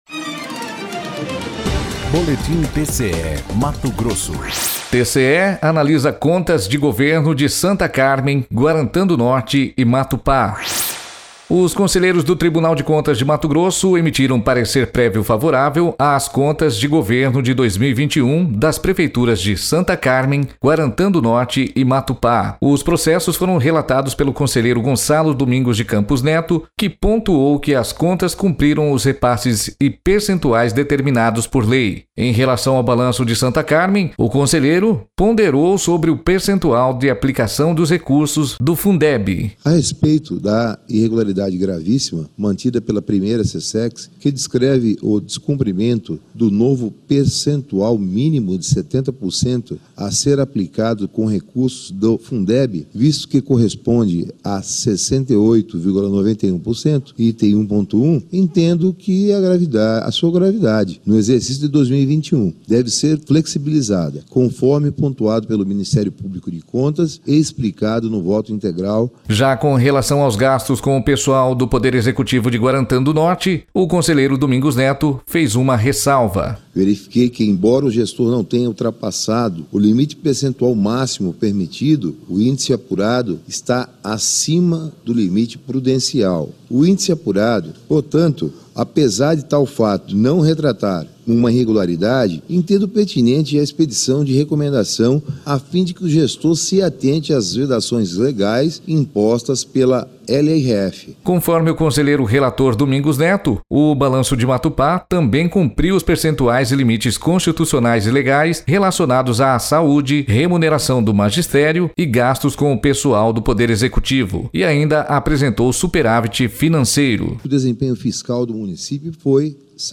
Sonora: Gonçalo Domingos de Campos Neto – conselheiro do TCE-MT